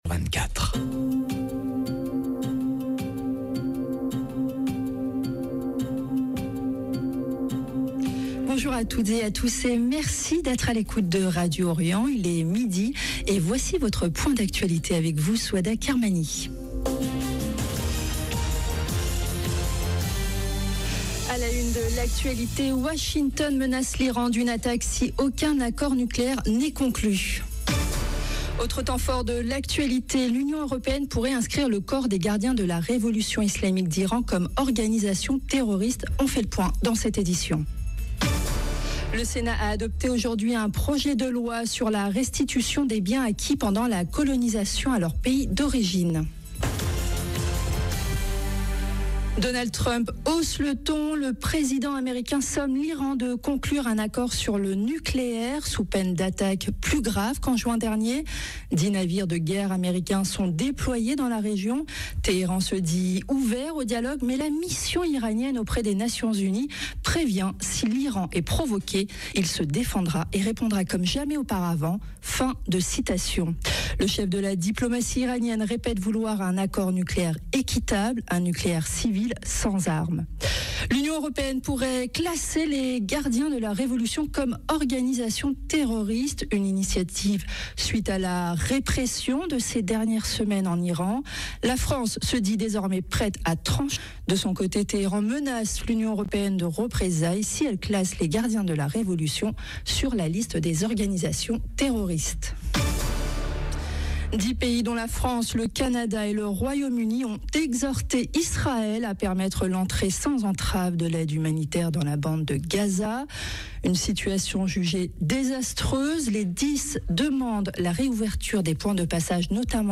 États-Unis Iran France Union européenne 29 janvier 2026 - 8 min 44 sec France : restitution des biens coloniaux, Iran: menace américaine d’attaque Radio Orient Journal de midi Les titres : A la une de l’actualité, Washington menace l’Iran d’une attaque si aucun accord nucléaire n’est conclu.